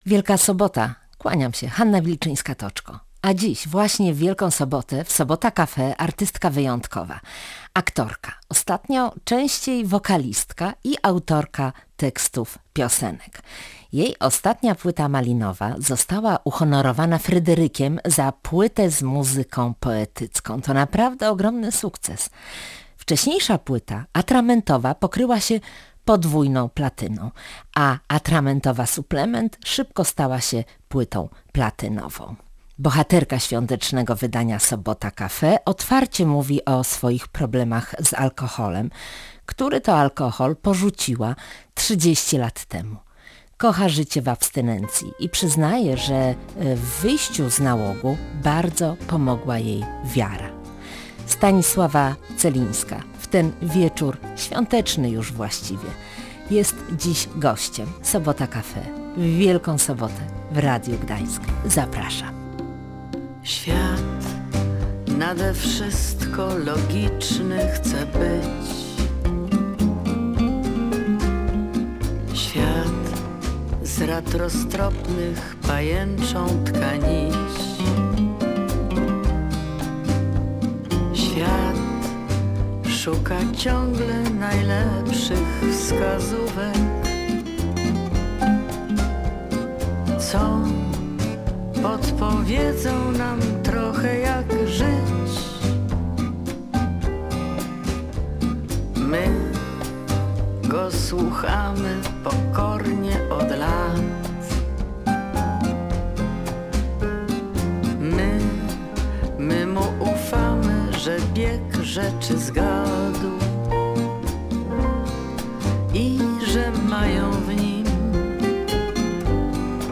– Wiem, że te piosenki mają dla ludzi walor terapeutyczny. W dzisiejszych głośnych czasach potrzebujemy takiej muzyki i łagodnego przekazu – mówiła w audycji Stanisława Celińska.